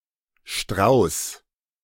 German: [ʃtʁaʊs]
De-Strauß.ogg.mp3